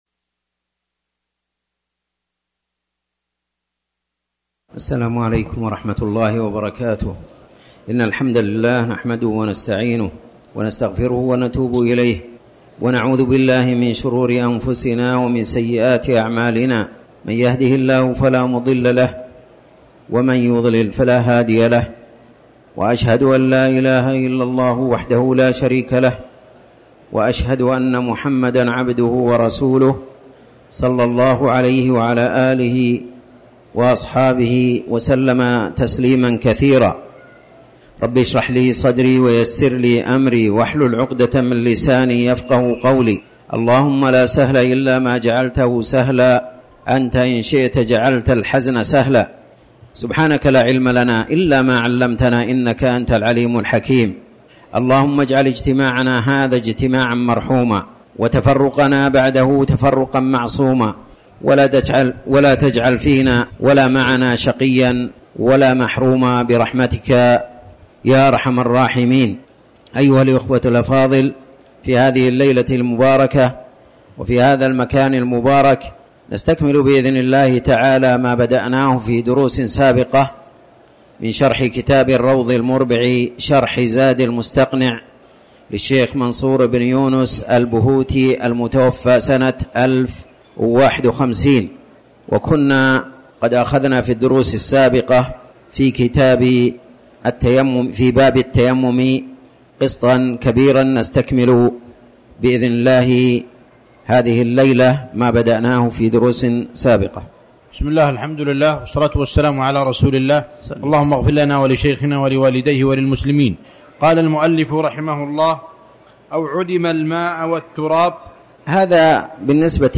تاريخ النشر ٢٩ جمادى الأولى ١٤٤٠ هـ المكان: المسجد الحرام الشيخ